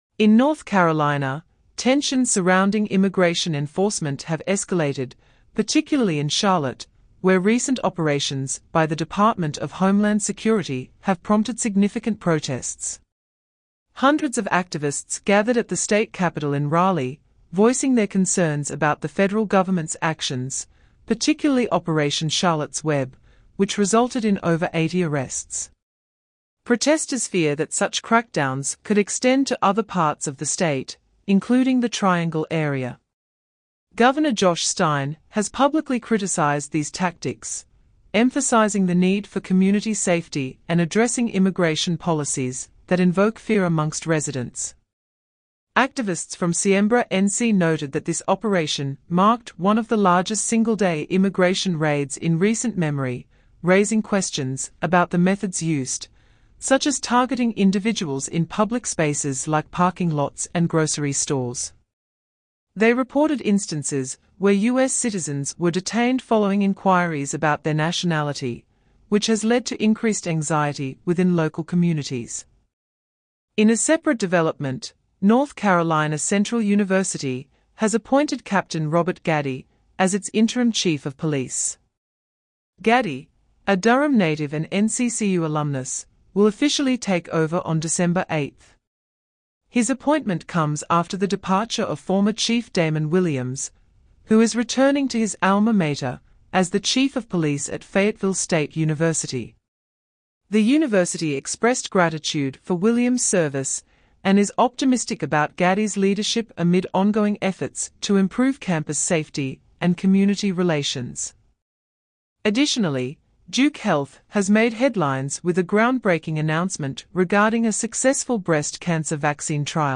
Regional News